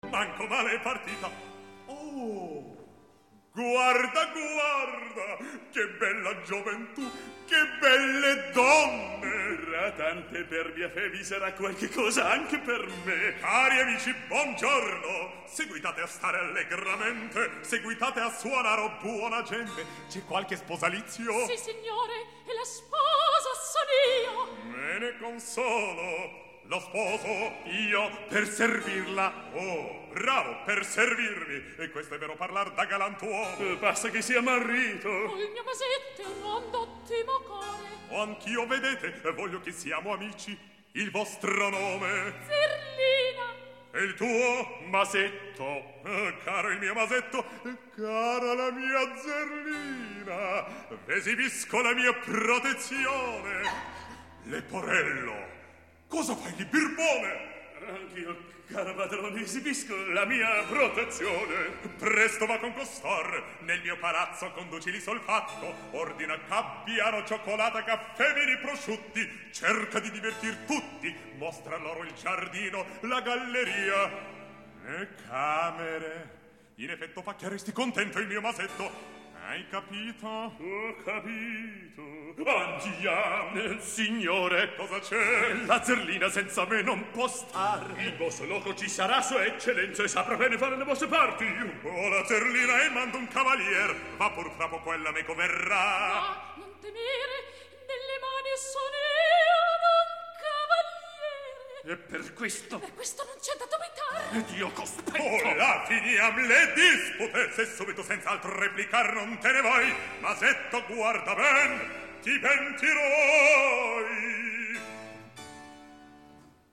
Запись: июнь-июль 1966 года, Лондон.